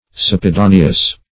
Search Result for " suppedaneous" : The Collaborative International Dictionary of English v.0.48: Suppedaneous \Sup`pe*da"ne*ous\, a. [Pref. sub- + L. pes, pedis, a foot: cf. L. suppedaneum a footstool.] Being under the feet.